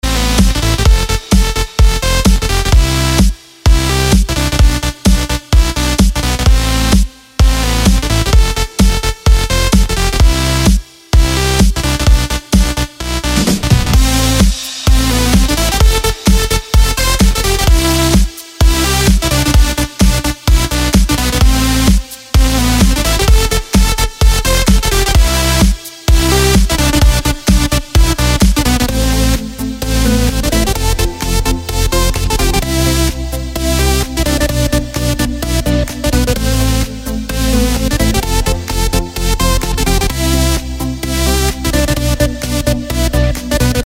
• Качество: 256, Stereo
Electronic
без слов
house